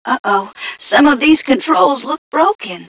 1 channel
mission_voice_m4ca016.wav